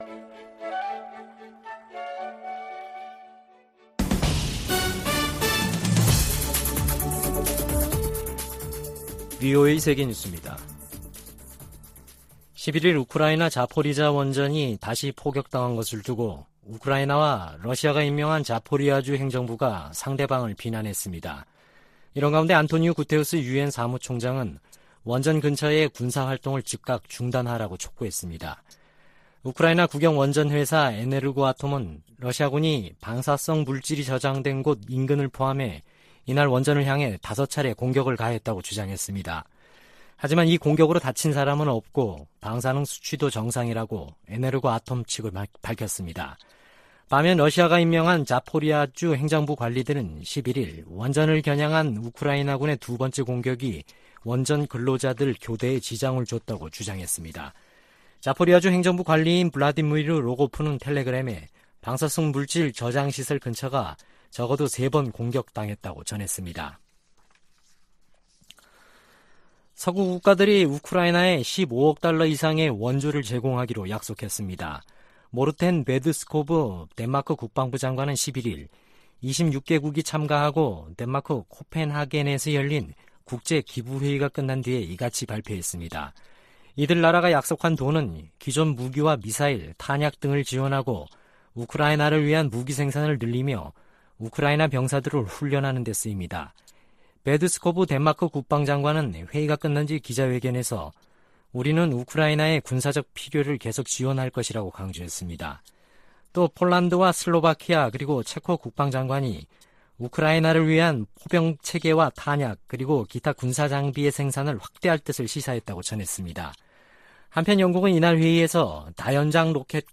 VOA 한국어 아침 뉴스 프로그램 '워싱턴 뉴스 광장' 2022년 8월 12일 방송입니다. 미 국무부는 중국의 사드와 관련한 한국에 대한 이른바 3불1한 주장은 부적절하다고 지적했습니다. 한국 대통령실 측은 사드는 북한 핵과 미사일로부터 국민을 지키기 위한 자위 방어 수단이라며 협의 대상이 결코 아니라고 강조했습니다. 북한의 거듭된 탄도미사일 발사가 한반도의 긴장을 고조시키고 있다고 아세안지역안보포럼 외교장관들이 지적했습니다.